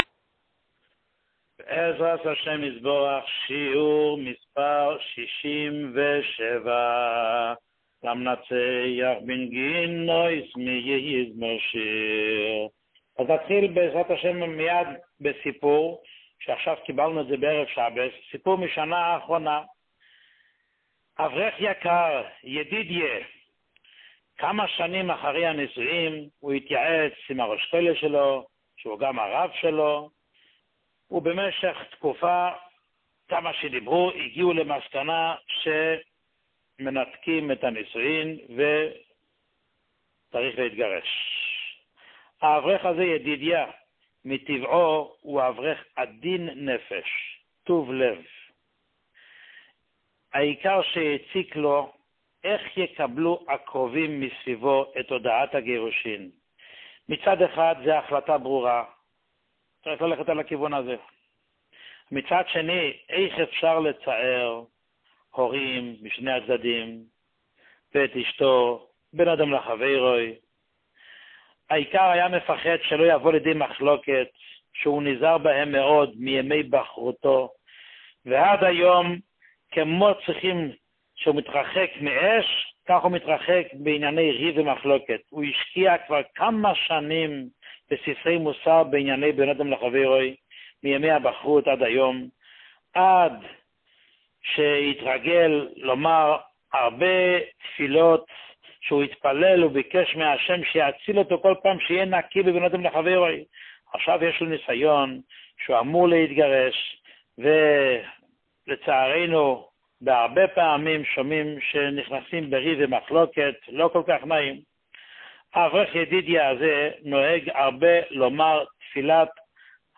שיעור 67